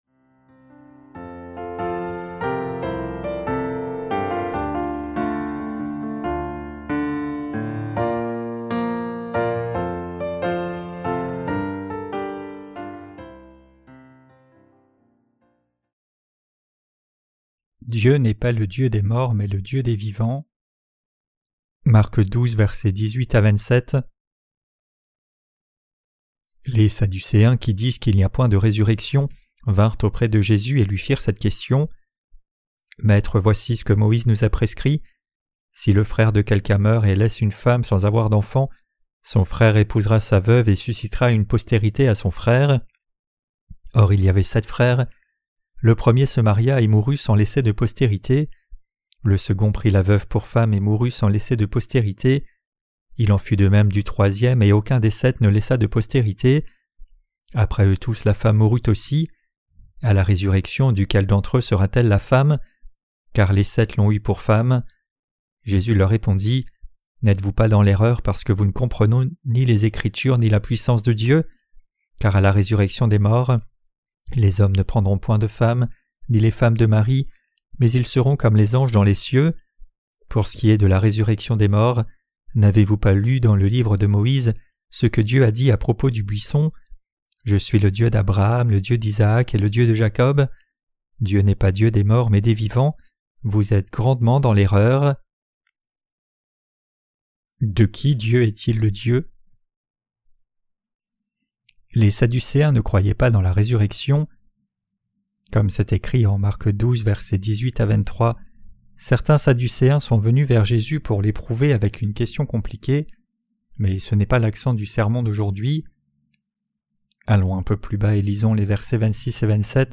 Sermons sur l’Evangile de Marc (Ⅲ) - LA BÉNÉDICTION DE LA FOI REÇUE AVEC LE CŒUR 5.